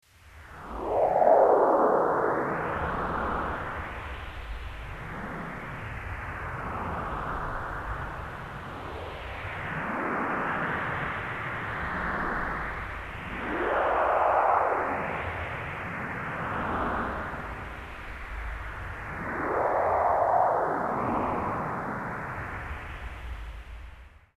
Martian_wind.mp3